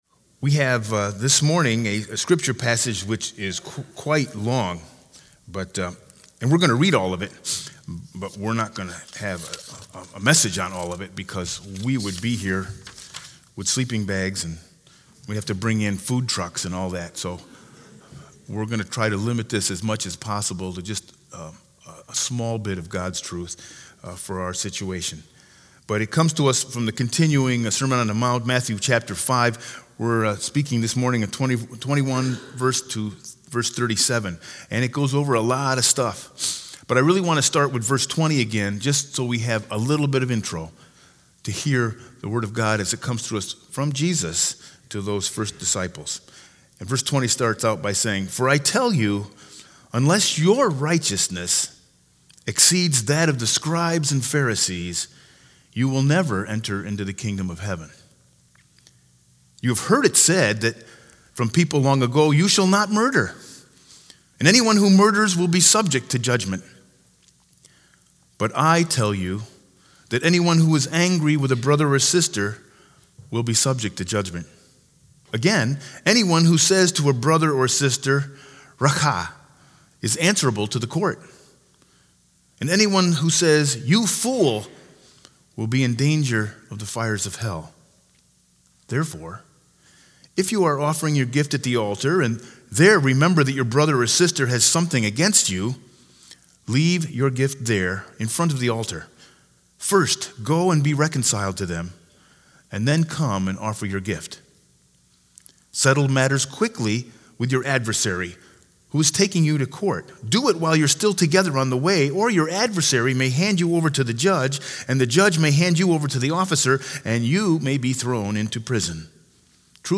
Sermon 2-16-20 with Scripture Lesson Matthew 5_21-37